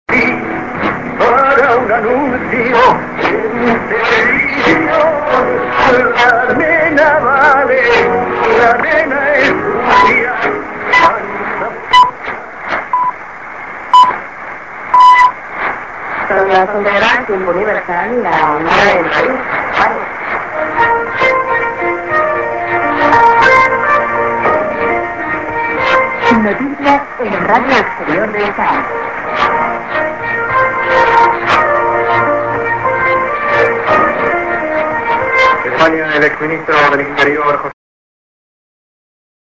Mid music->TS->ID(women)->